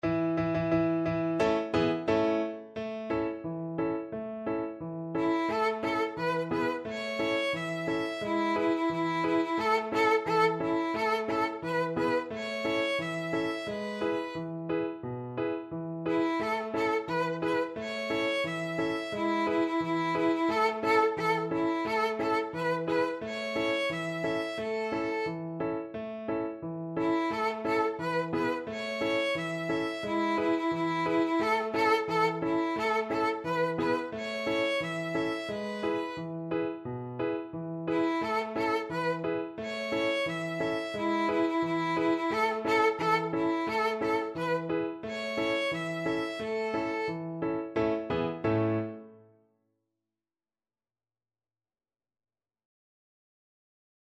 2/4 (View more 2/4 Music)
Steady march =c.88